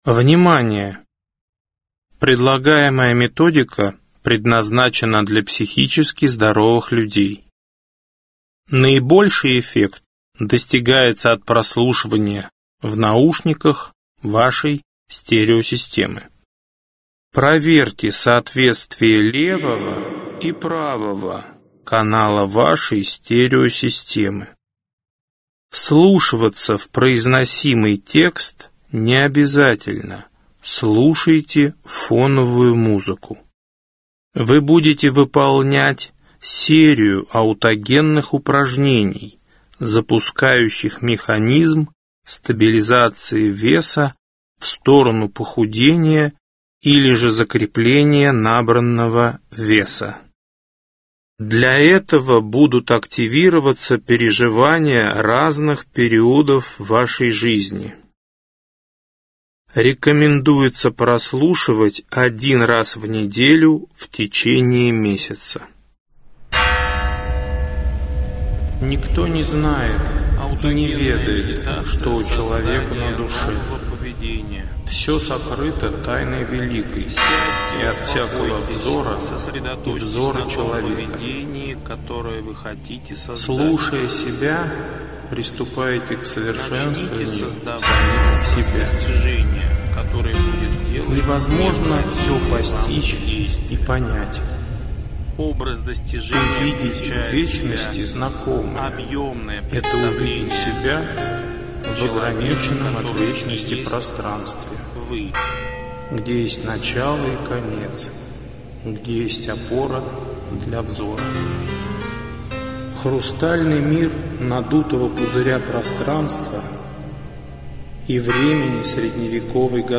Аудиокнига Восстанавливающие психотехники. Диск 5. Стабилизация веса, похудение.